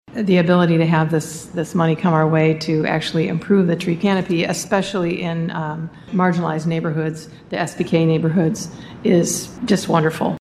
Vice Mayor Jeanne Hess commented.